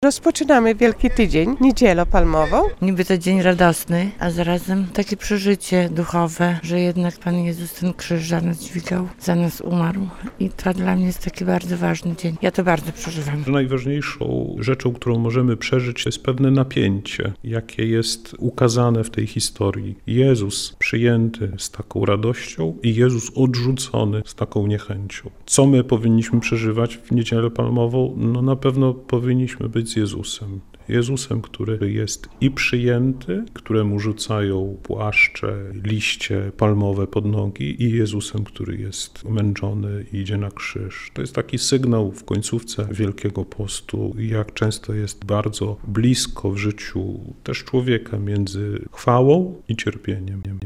Niedziela Palmowa w Kościele katolickim - relacja